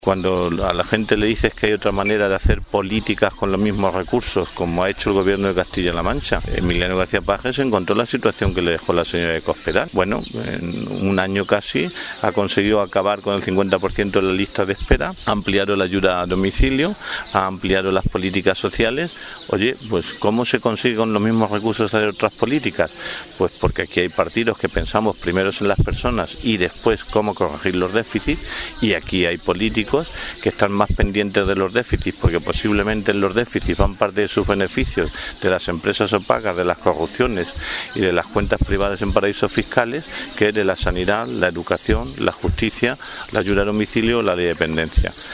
Encuentro con militantes en Piedrabuena
Cortes de audio de la rueda de prensa